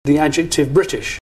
Natives put the main stress at the beginning, Ádjective.
Here are examples of the native pronunciation: